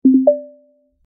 inbox-notification.wav